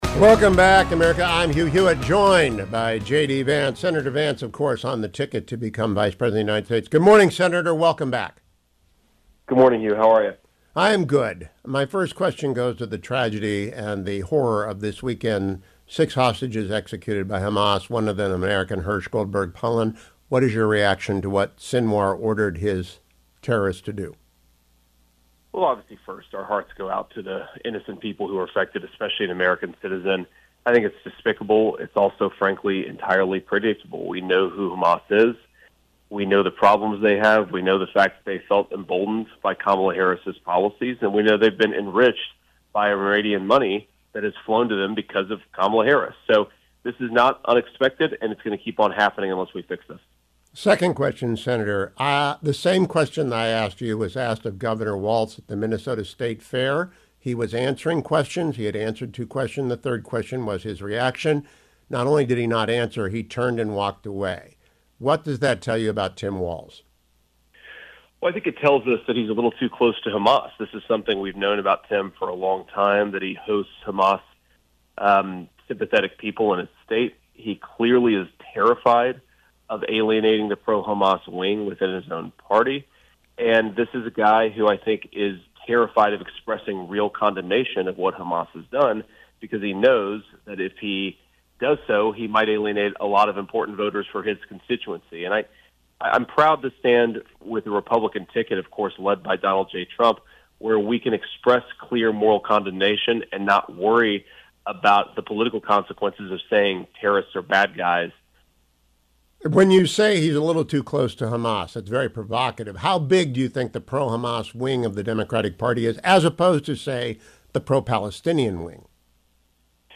Senator J.D. Vance joined me today for 20-25 minutes.